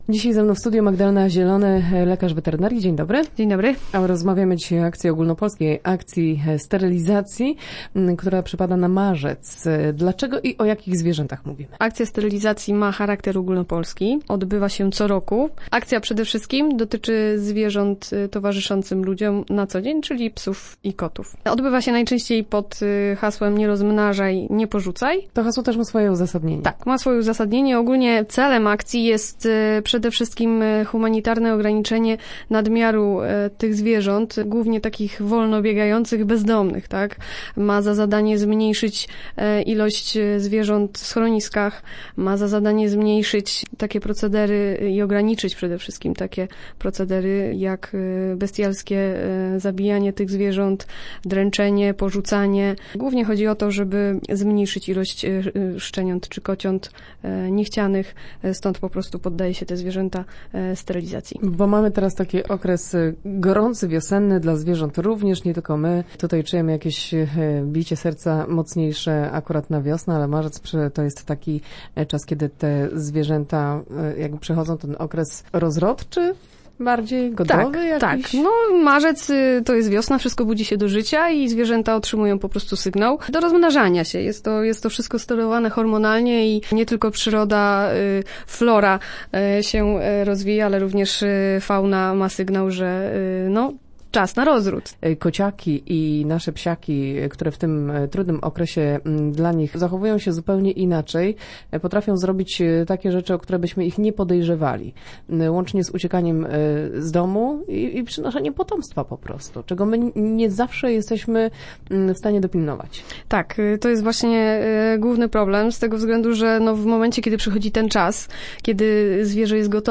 Start arrow Rozmowy Elki arrow Akcja sterylizacji